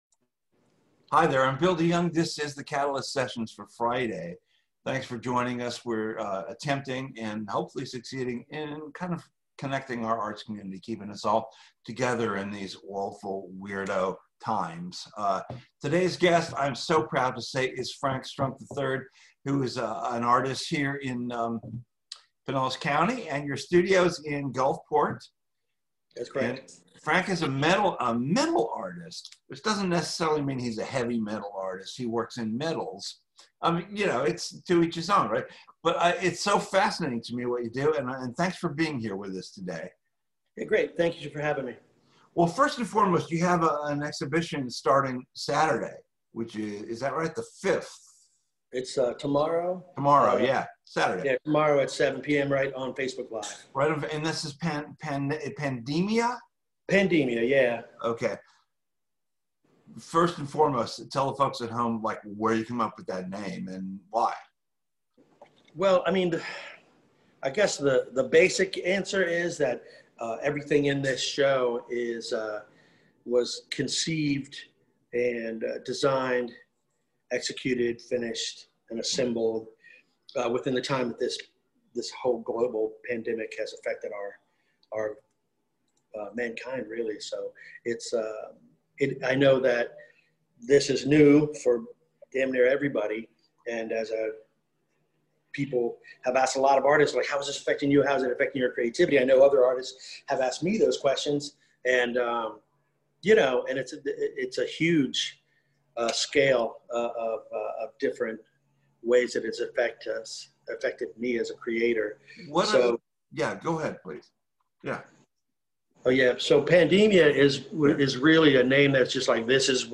Catalyst Sessions interview